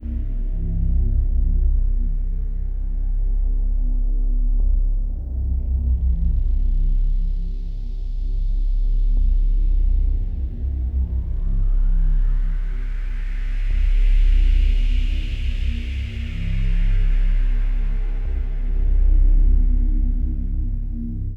mysteryVortexSFX.wav